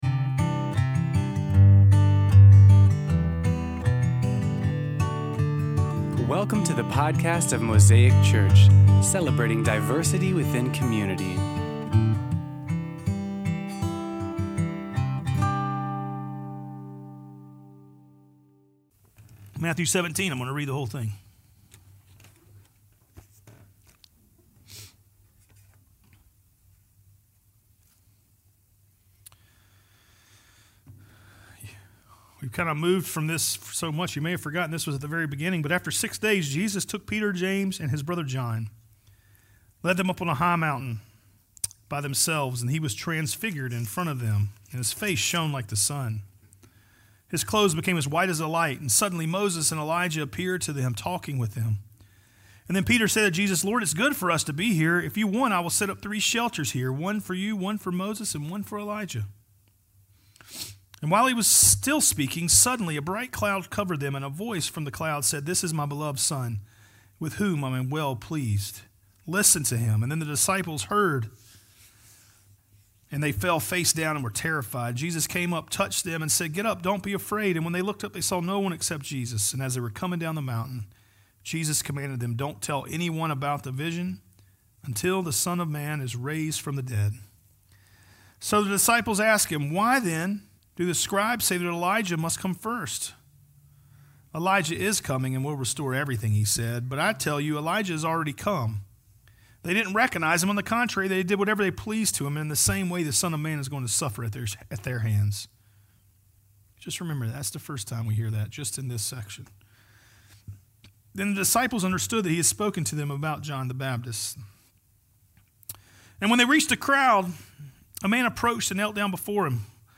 Sermon Series on Matthew's Gospel